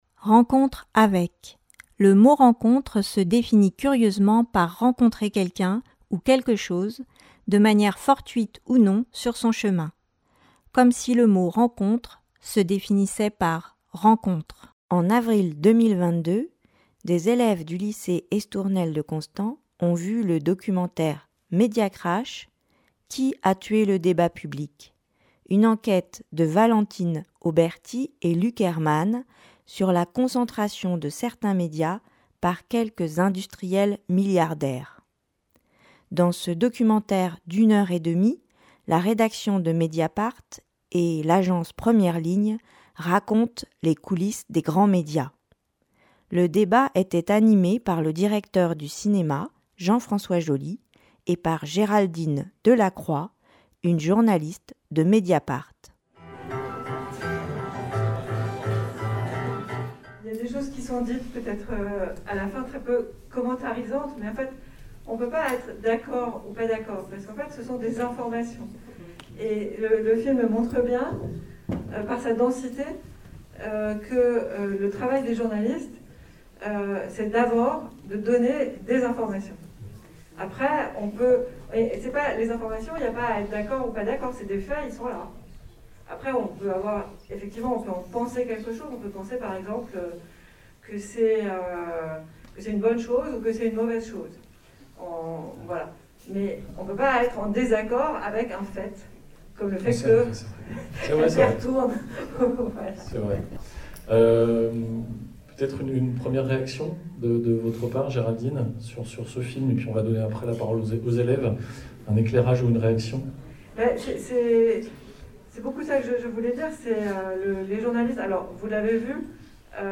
rencontre avec une journaliste de Mediapart après la projection de Media crash.mp3